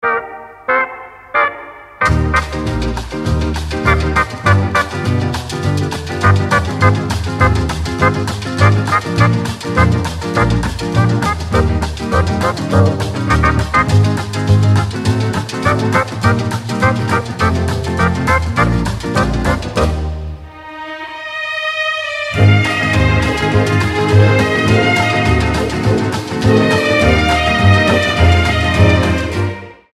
• Качество: 320, Stereo
позитивные
веселые
без слов
Lounge
джаз
60-е